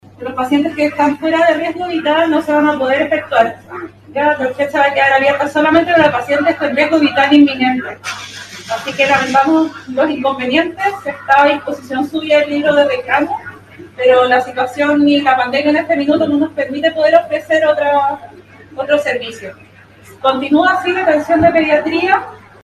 “La pandemia en este minuto no nos permite poder ejercer otros servicios”, señaló una de las profesionales que comunicó la decisión a los pacientes en el ingreso al centro médico.